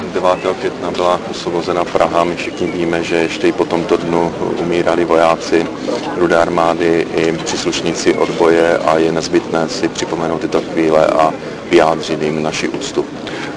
Vyjádření premiéra Petra Nečase po skončení pietního aktu na Olšanech